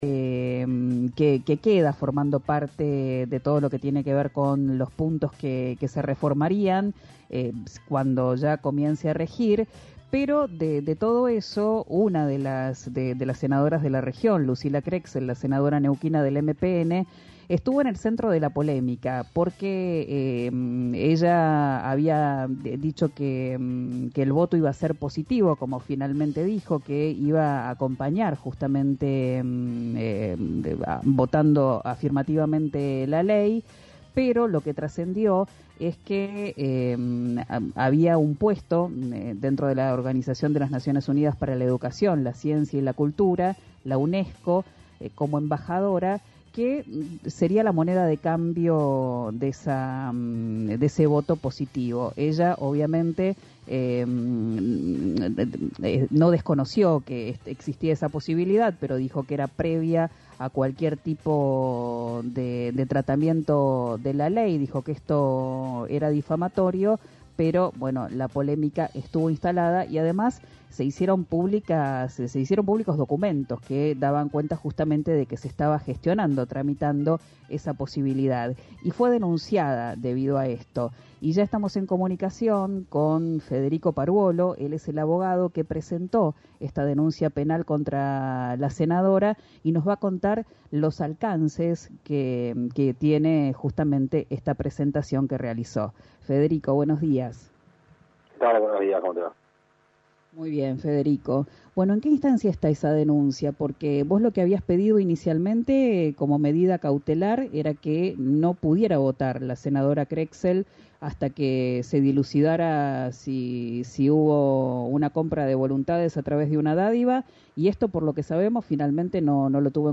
Escuchá al abogado en RÍO NEGRO RADIO